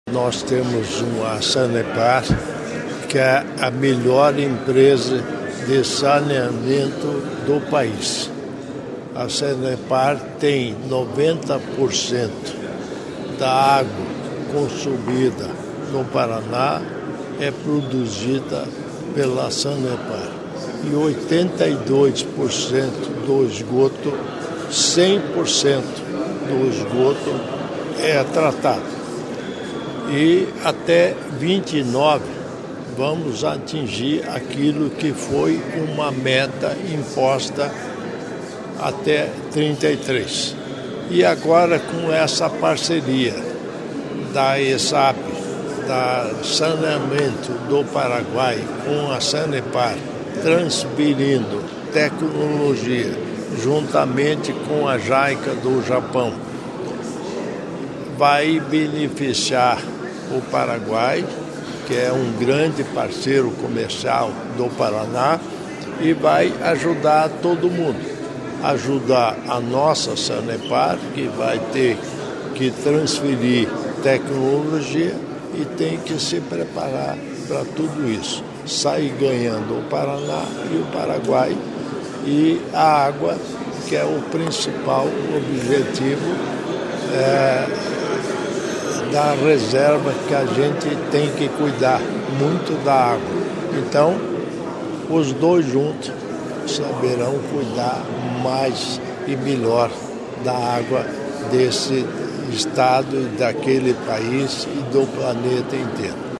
Sonora do vice-governador Darci Piana sobre a parceria da Sanepar com a ESSAP, do Paraguai